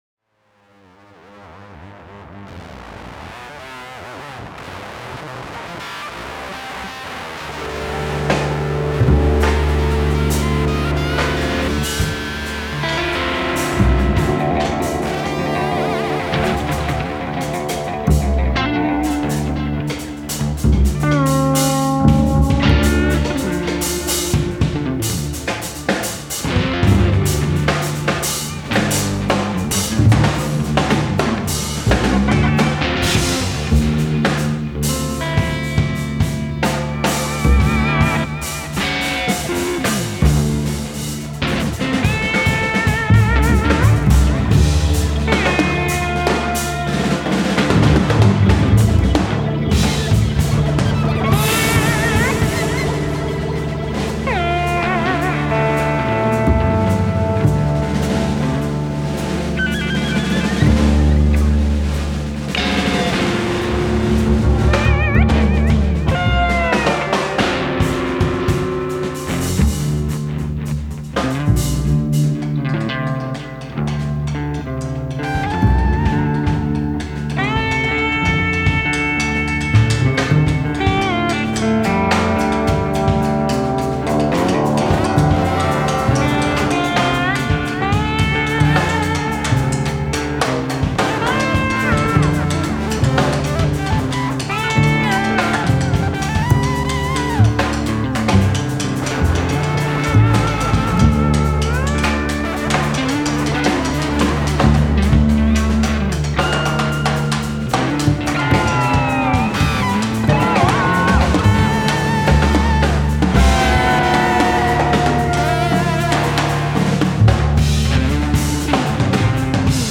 guitars, saxophone
baritone saxophone
cornet
vocals